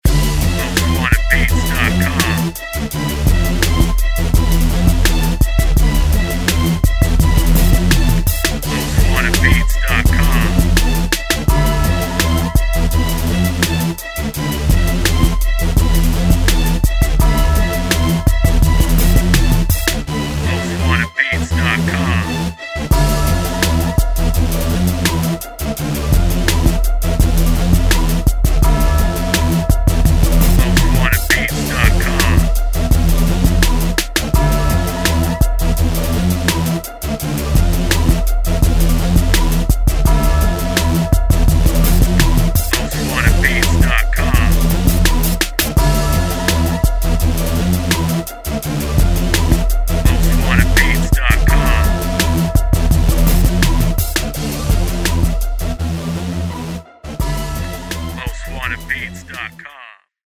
DIRTY SOUTH INSTRUMENTAL